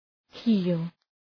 heal Προφορά